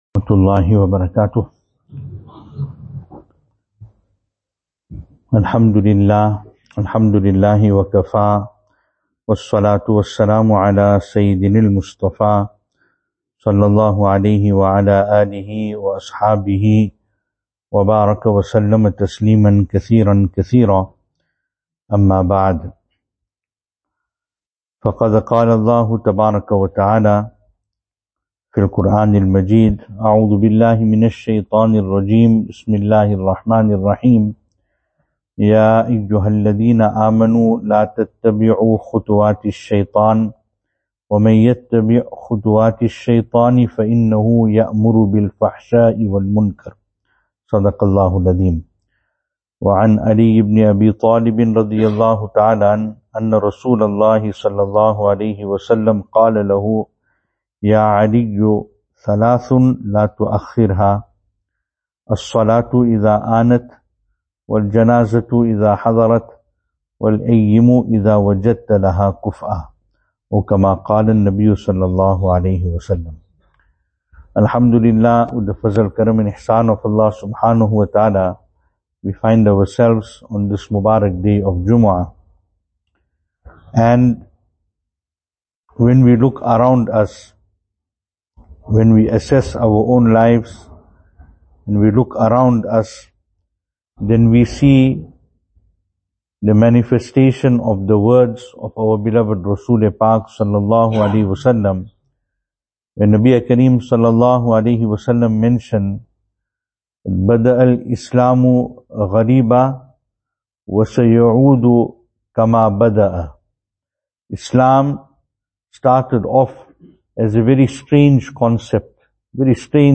2024-08-16 Don’t delay 3 things Venue: Albert Falls , Madressa Isha'atul Haq Service Type: Jumu'ah « 1.